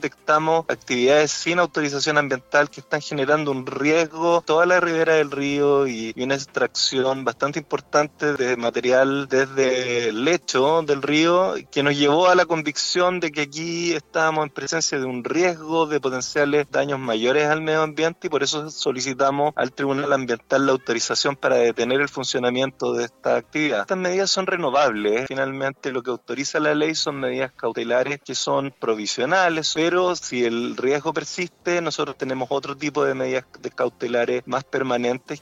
En conversación con Radio Sago, el superintendente del Medio Ambiente, Cristóbal de La Maza, detalló los motivos por los cuales solicitaron detener las obras de extracción a la empresa Dowling & Schilling S.A en el cauce del río Rahue, sector Cancura.